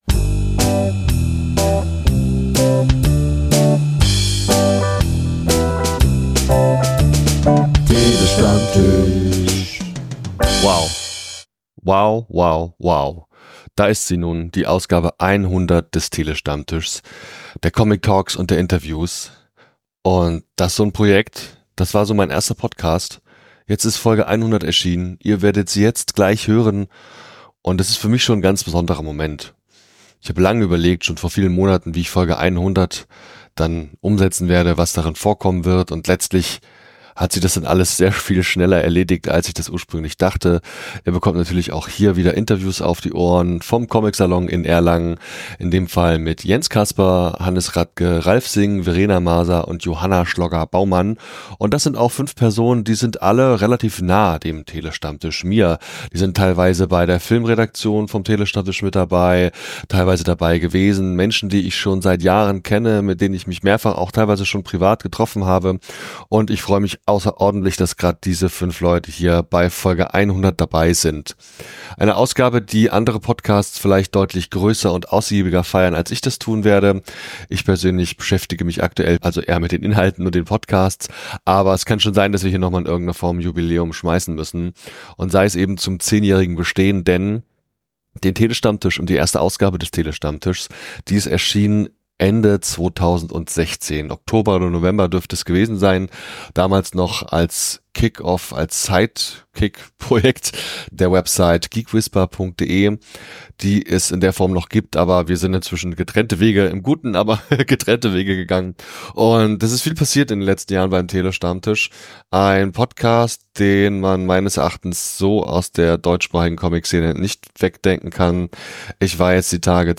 Wie funktioniert ein Comeback in die Comicszene? Wie wird man Vertragspartner*in bei Webtoon? Wie funktioniert Diversität in Comics? Wie übersetzt man einen Manga? Was ist Graphic Recording? - Das und nochmal DEUTLICH mehr erfahrt ihr in den neusten Interviews, die ich in diesem Jahr...
Ich habe vor Ort sehr viel gearbeitet und viele Stunden lang Interviews geführt und aufgezeichnet.